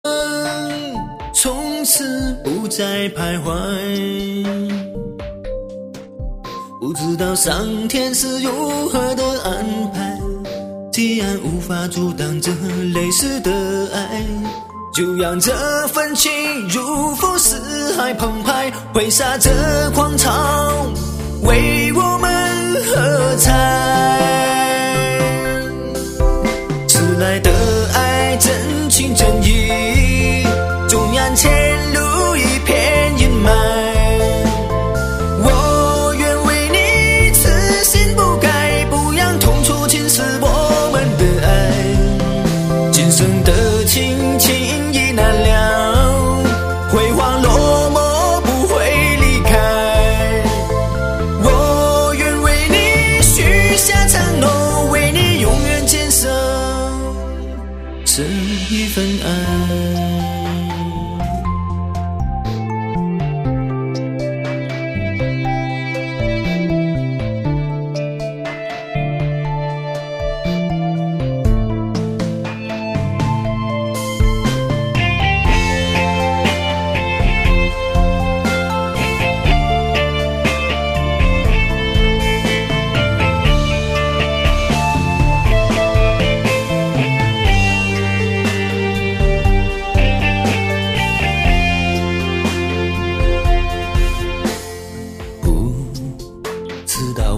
主打歌曲